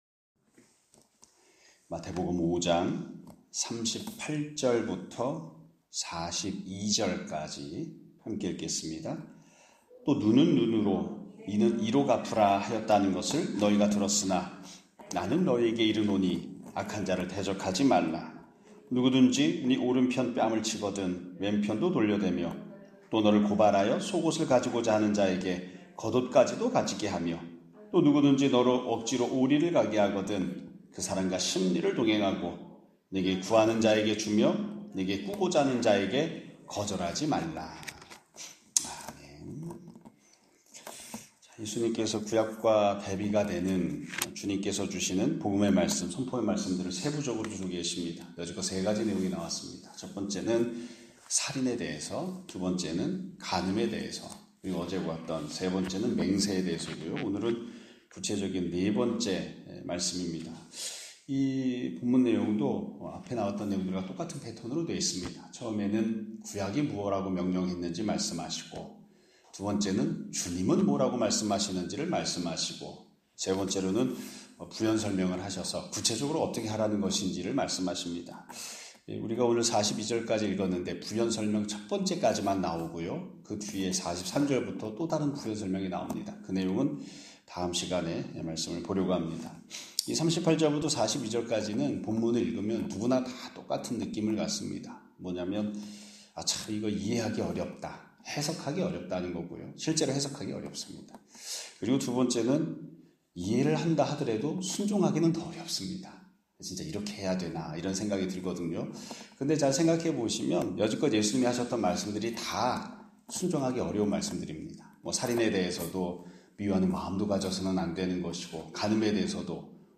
2025년 5월 30일(금요일) <아침예배> 설교입니다.